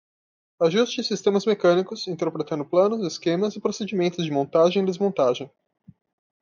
Pronounced as (IPA)
/mõˈta.ʒẽj̃/